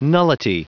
Prononciation du mot : nullity
nullity.wav